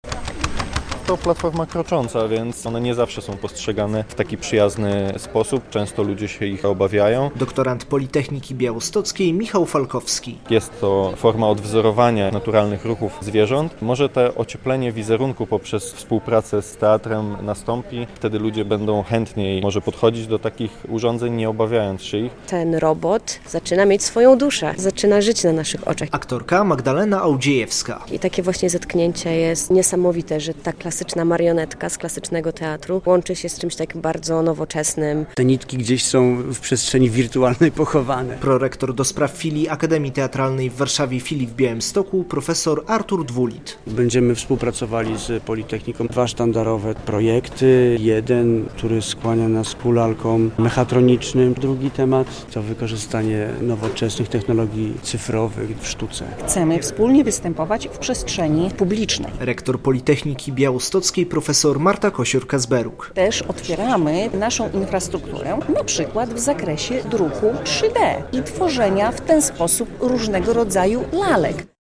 Politechnika Białostocka będzie współpracować z Akademią Teatralną - relacja